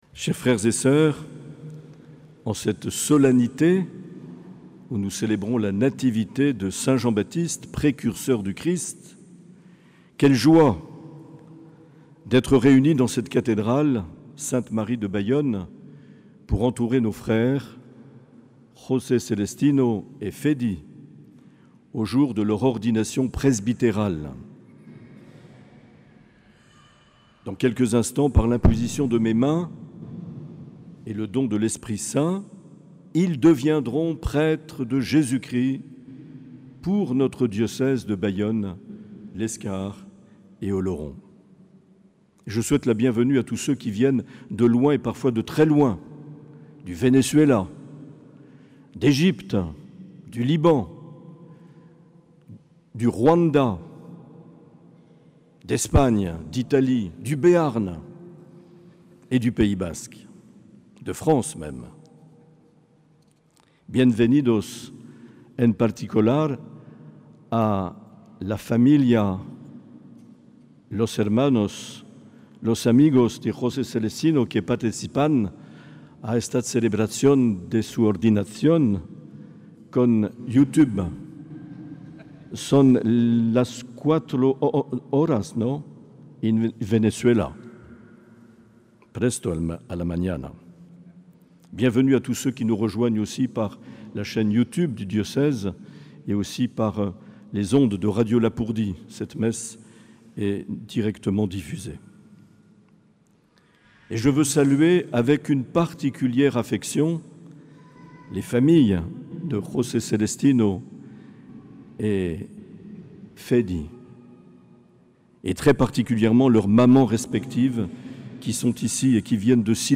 Accueil \ Emissions \ Vie de l’Eglise \ Evêque \ Les Homélies \ 24 juin 2023 - Cathédrale de Bayonne
Une émission présentée par Monseigneur Marc Aillet